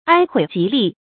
哀毀瘠立 注音： ㄞ ㄏㄨㄟˇ ㄐㄧˊ ㄌㄧˋ 讀音讀法： 意思解釋： 形容因居親喪悲損其身，瘦瘠如骨骸支立 出處典故： 唐 張鷟《朝野僉載 補輯》：「（崔渾）丁母艱，勺飲不入口， 哀毀瘠立 。」